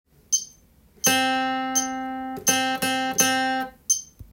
２拍伸ばす
譜面は全てドの音だけで表記していますので
まず、１つめのリズム　は２拍伸ばして８分音符を２つ弾き１つ４分音符